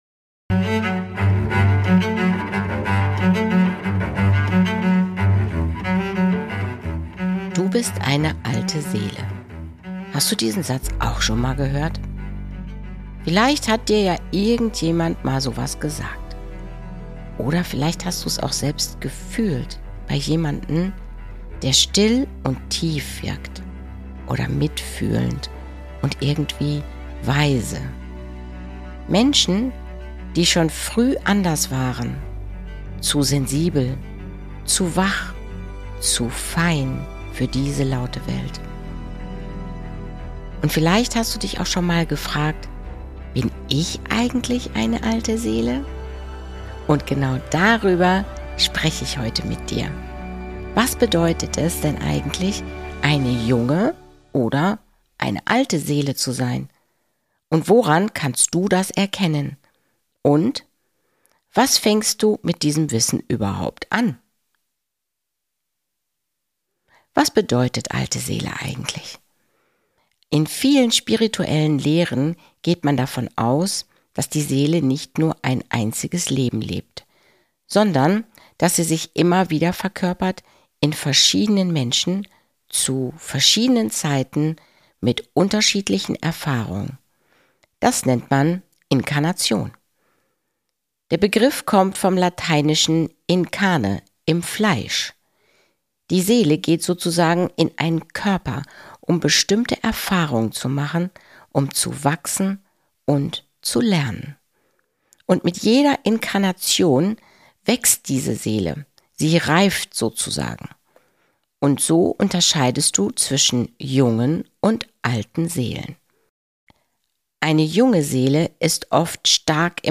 Eine ruhige, tiefgehende Folge für alle, die spüren, dass